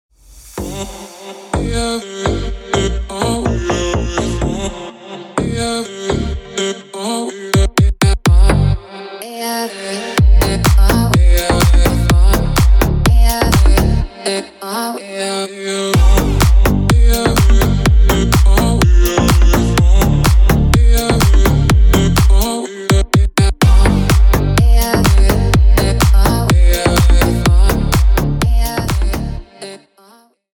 Танцевальные
без слов